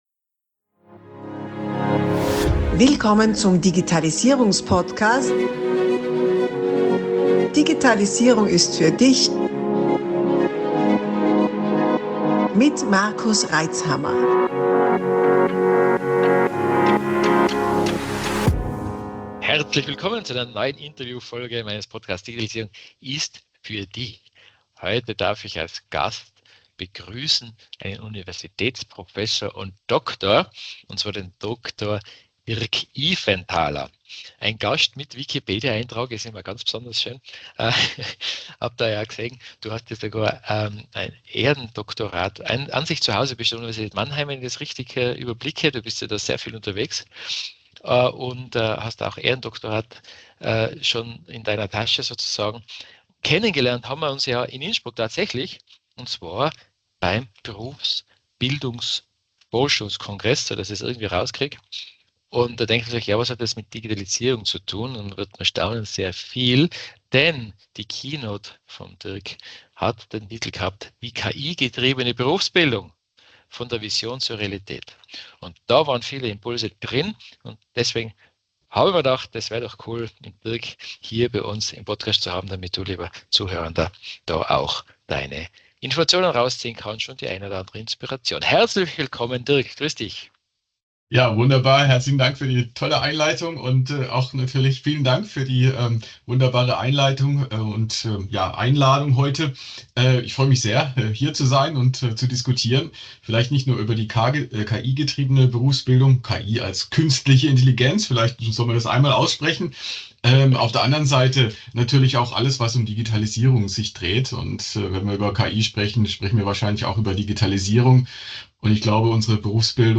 Interviewfolge